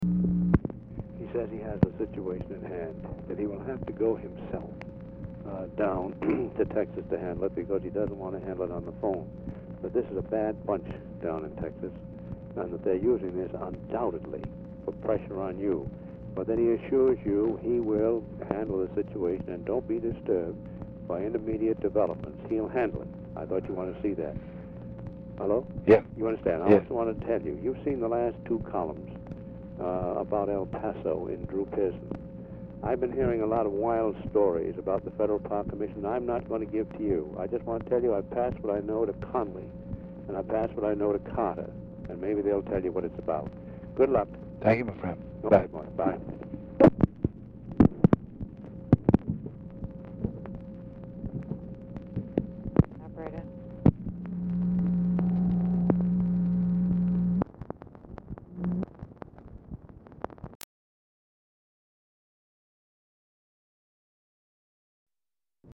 Oval Office or unknown location
RECORDING STARTS AFTER CONVERSATION HAS BEGUN
Telephone conversation
Dictation belt